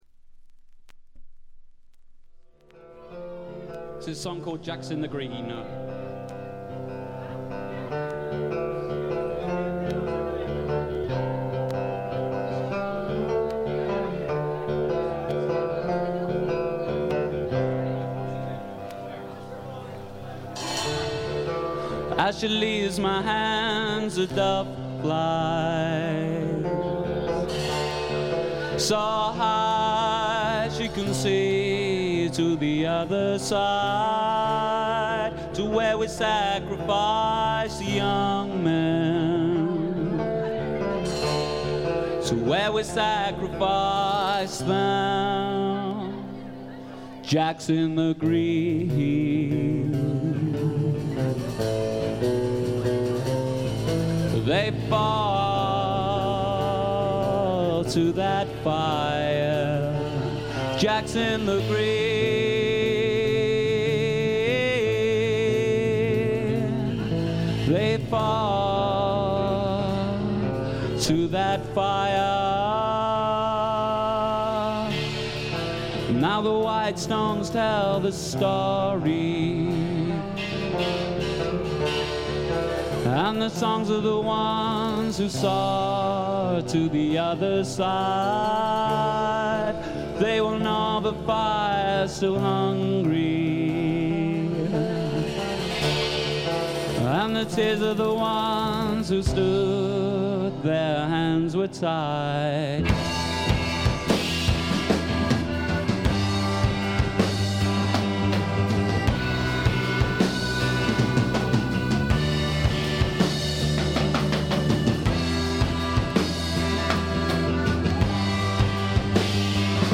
英国のバンドの小さなクラブでののライヴ盤です。
素敵なフォーク・ロックを聴かせてくれます。
全編に鳴り響くメロデオンのチープな音がいい感じですね。
試聴曲は現品からの取り込み音源です。
Recorded live at Moles Club, Bath: September 1988.